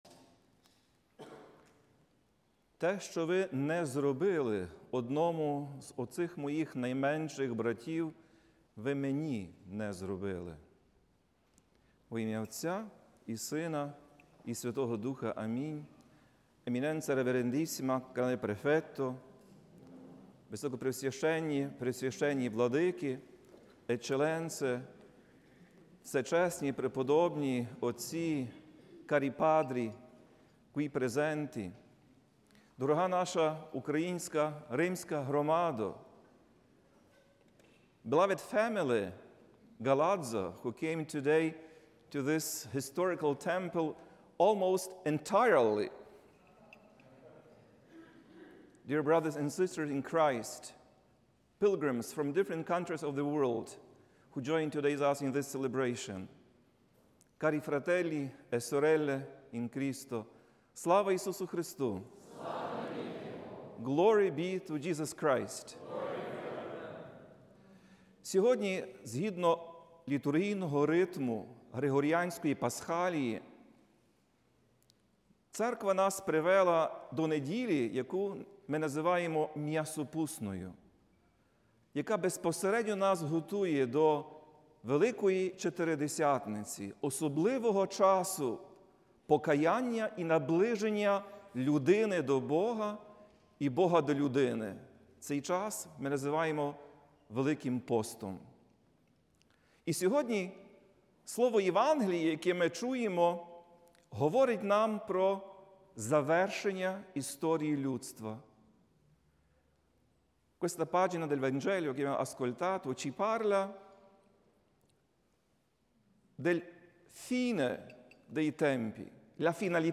У неділю, 8 лютого, Блаженніший Святослав, Отець і Глава УГКЦ, очолив Божественну Літургію в патріаршому прокатедральному соборі Святої Софії в Римі.